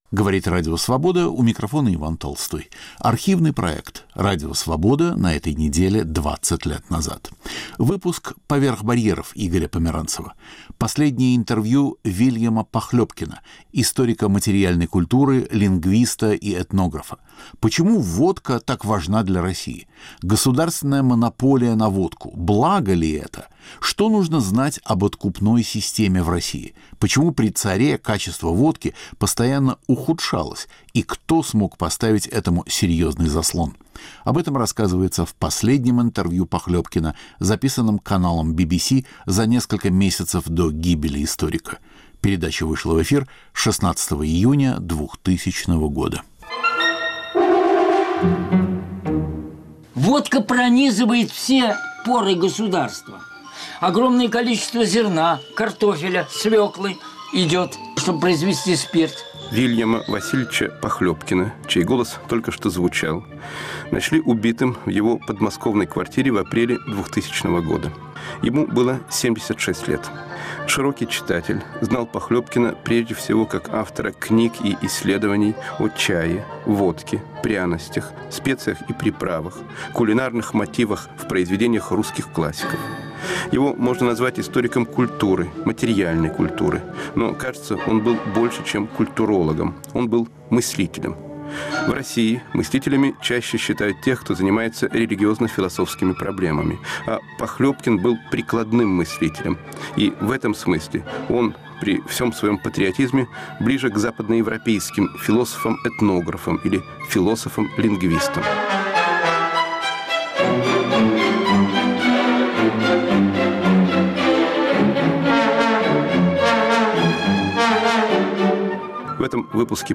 Радио Свобода на этой неделе 20 лет назад. Последнее интервью Вильяма Похлебкина
Архивный проект.